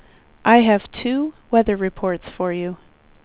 WindowsXP / enduser / speech / tts / prompts / voices / sw / pcm8k / weather_3.wav